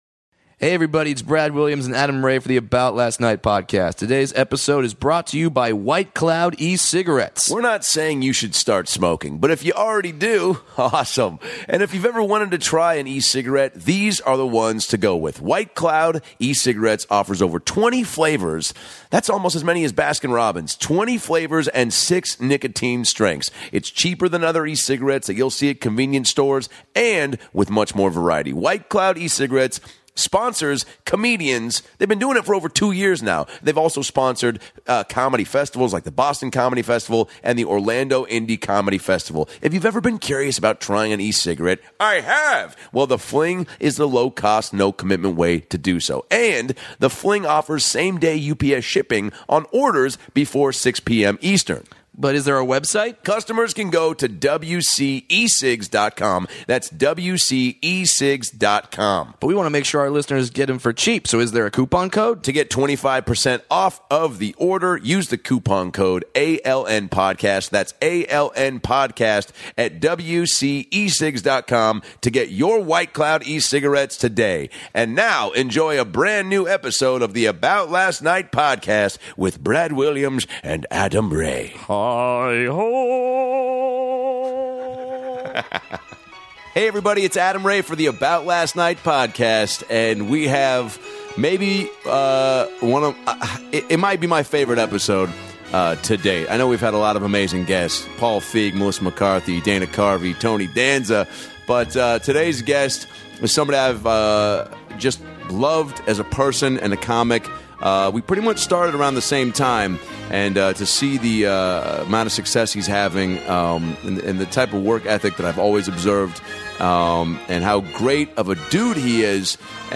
Jerrod Carmichael stops by to talk about growing up in North Carolina, creating his own sitcom with NBC, the experience of making an HBO standup special at The Comedy Store, and drops insights about comedy and life that will keep you thinking for days. He's one of the strongest and most original comedic voices in the game, and after listening to this conversation, you'll understand why Hollywood feels the same.